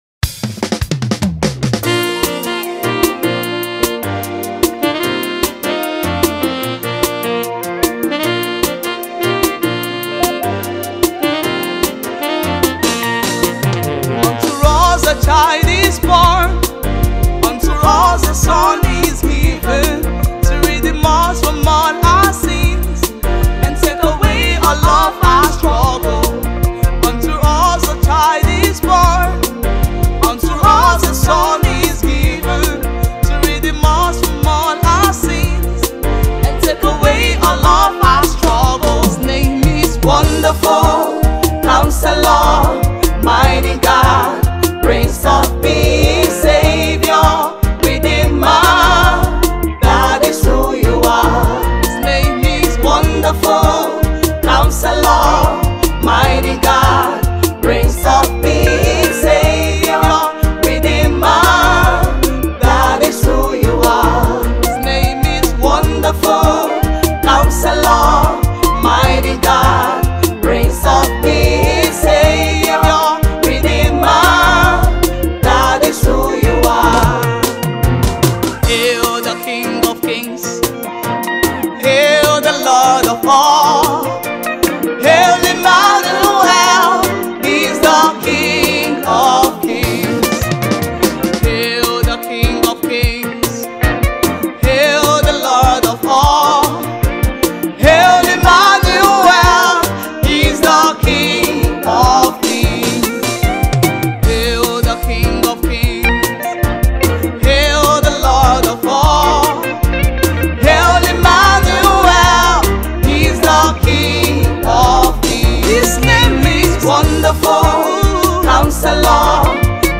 gospel music
heartwarming and soul-stirring holiday single
beautiful and timeless holiday song
soulful vocals